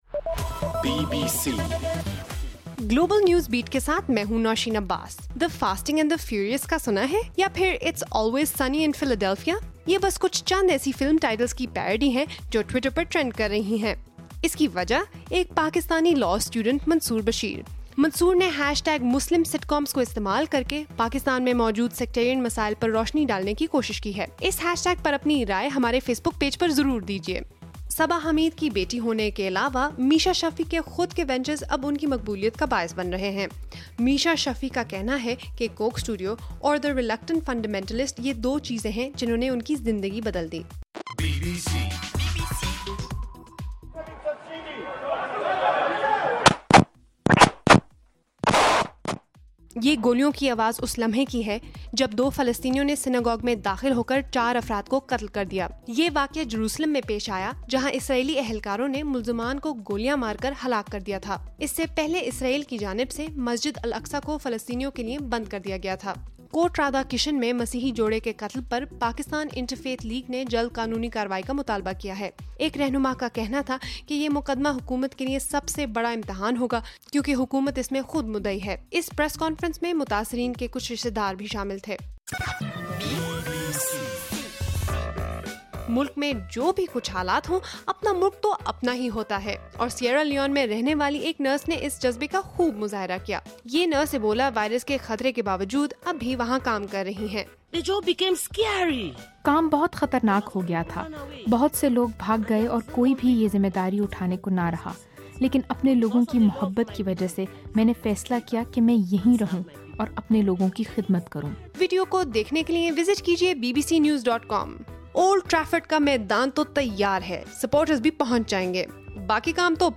نومبر 18: رات 8 بجے کا گلوبل نیوز بیٹ بُلیٹن